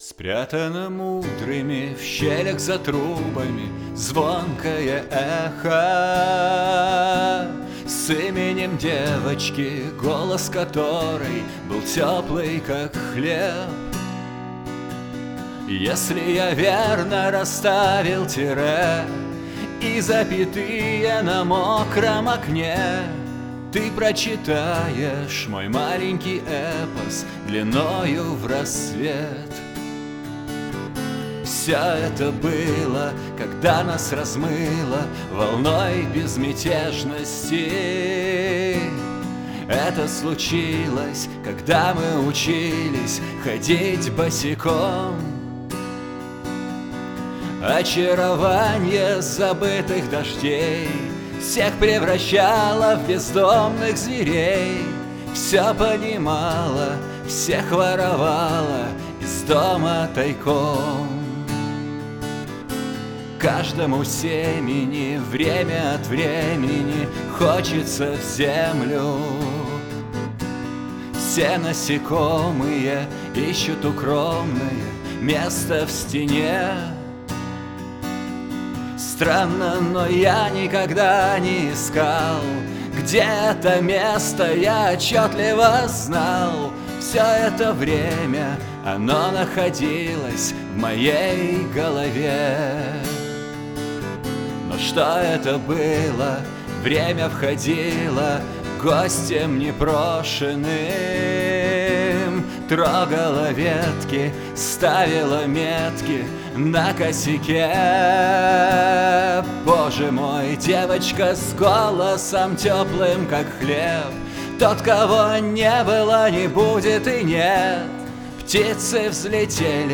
Альбом записан на домашней студии.